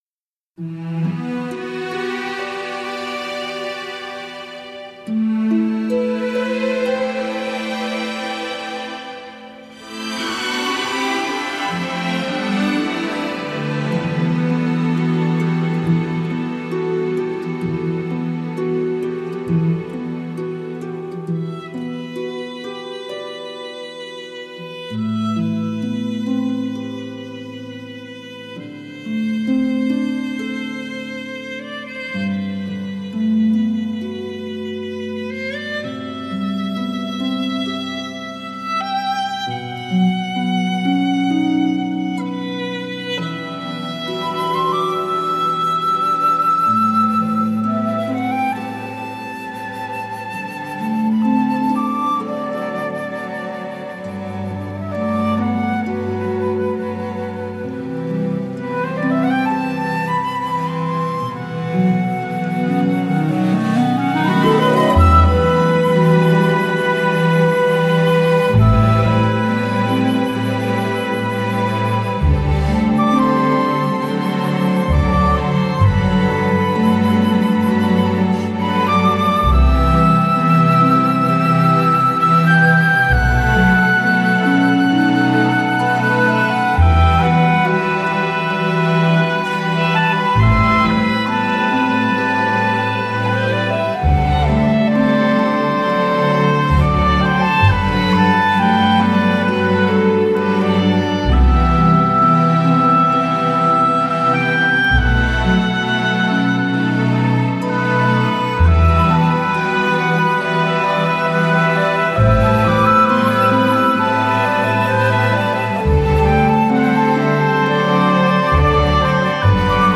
而原曲经过 重新演绎后，时而浩大合奏，时而以不同乐器主奏， 比原曲更加有丰富的层次感 和立体饱满度！